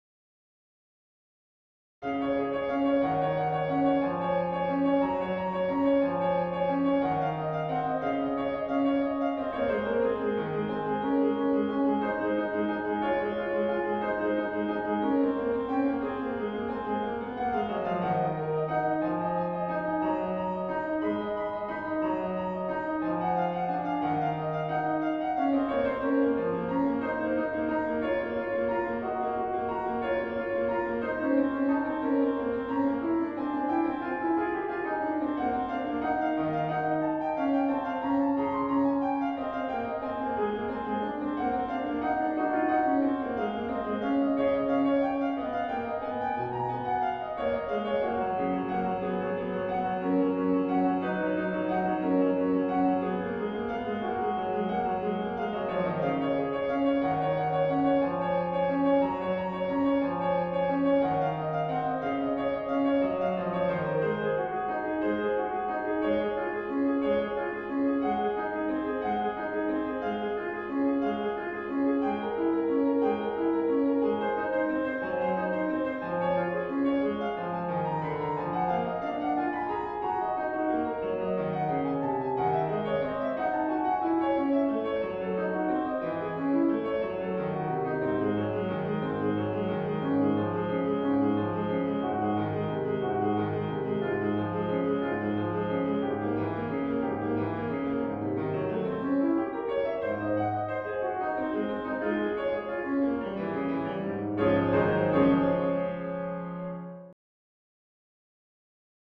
This Prelude and Fugue has, as you can see, a considerable number of sharps; in fact it has seven, moving every note of the scale up a semitone.